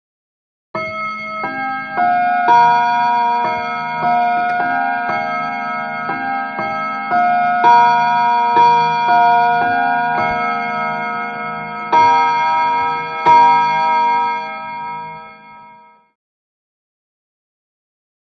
Clock Chimes Rings|Chime|Combination | Sneak On The Lot
Clock Chime; Mantel Clock Chimes 1 / 4 Hr, 1 / 2 Hr And 3 / 4 Hr. Two Different Clocks.